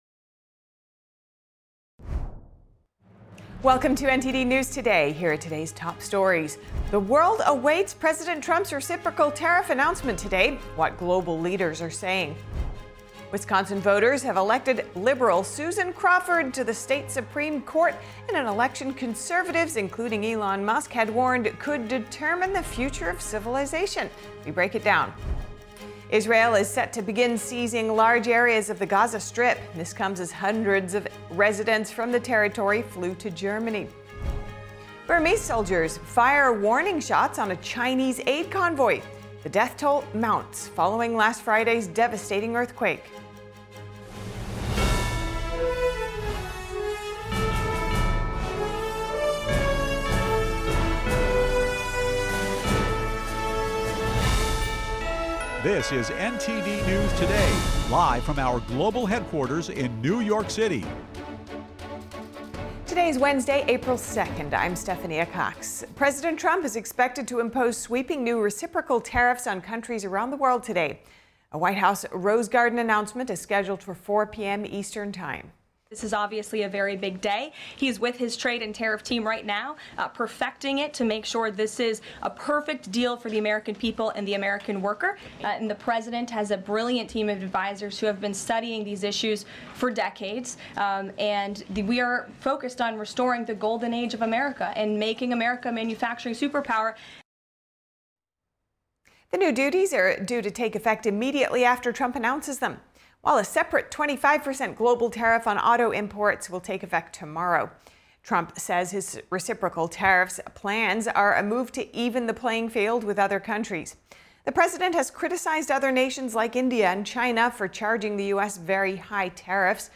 NTD-News-Today-Full-Broadcast-April-2-audio-converted.mp3